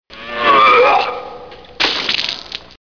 Vomit